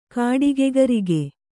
♪ kāḍigegarige